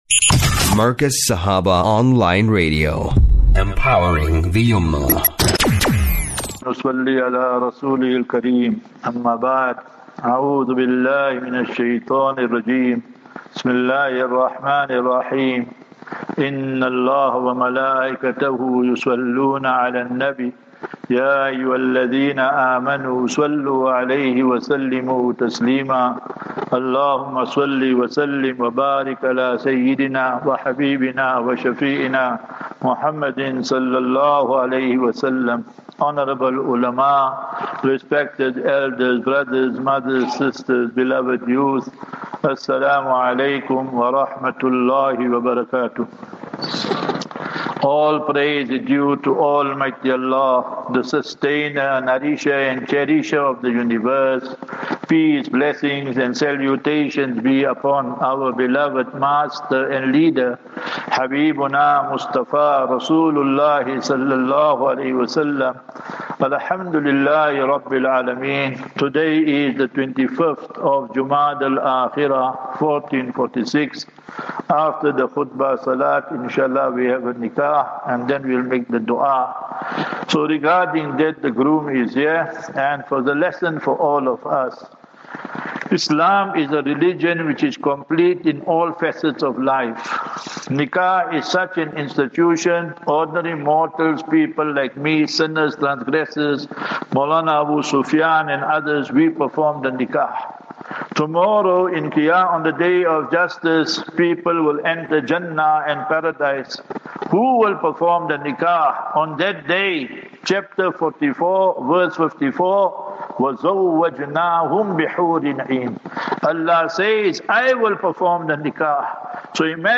Jumuah Lecture at Masjid Tariq Bin Ziyad (Linbro Park JHB)